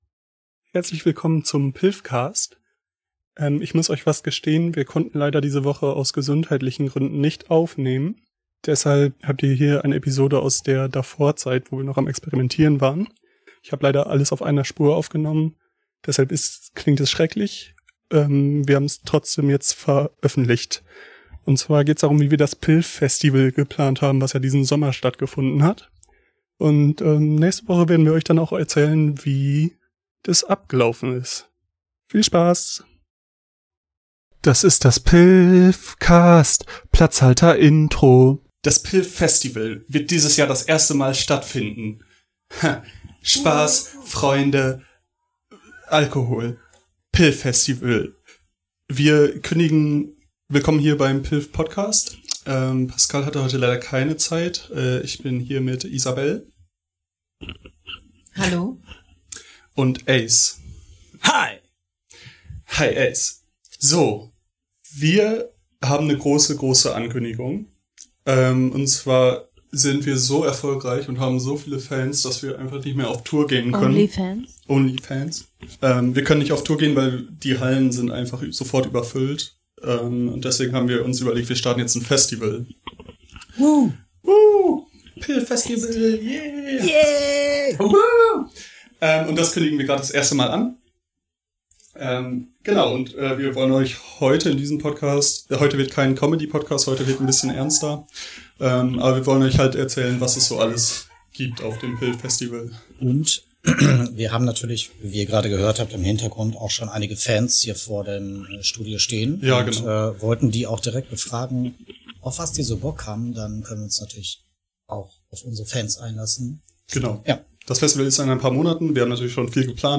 Diese Woche Planen wir das PYLFstival. Die Autioqualität ist heute etwas schlechter - sorry :(